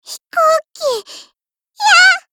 Cv-30133_warcry.mp3